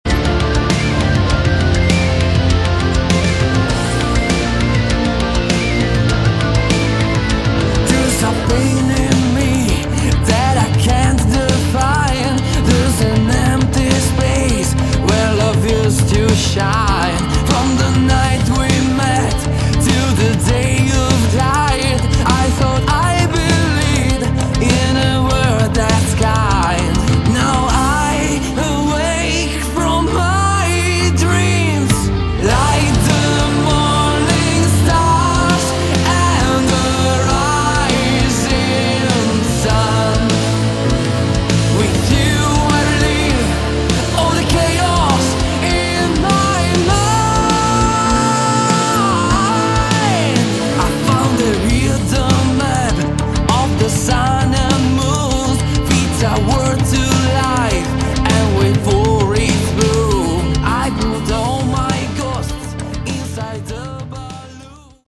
Category: Hard Rock
vocals
drums
guitars
bass
keyboards